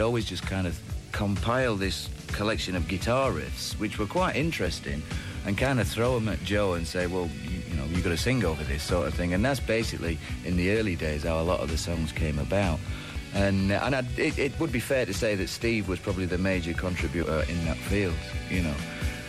Savage-on-compiling-riffs-and-ideas-in-early-days-with-Steve-being-main-contributor-Radio-1-June-1993.mp3